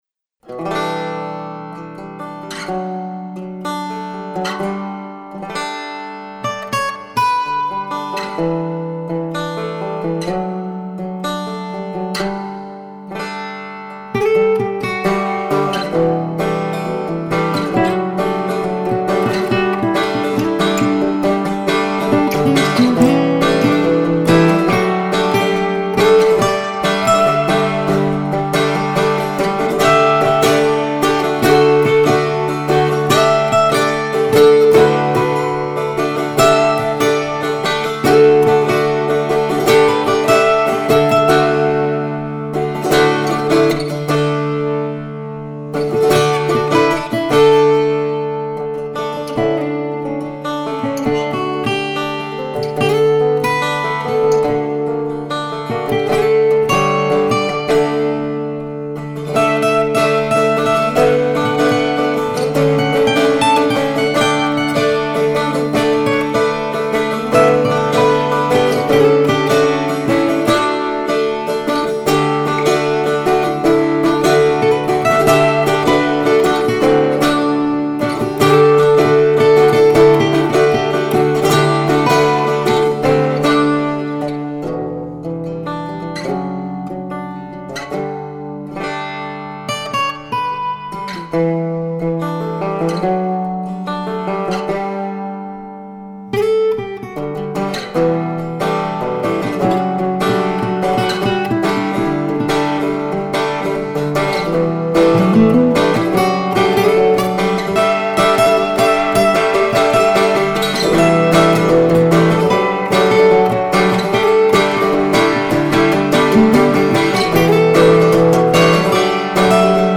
Re: (aussenjam) #37 - The Drone - Akustische Version
Ich habe eben nochmal einen Mixdown mit deutlich weniger Hall gemacht, falls Du mal Lust und Zeit hast reinzuhören und mir dann noch sagen würdest, ob Du es nun besser findest, wäre das sehr nett.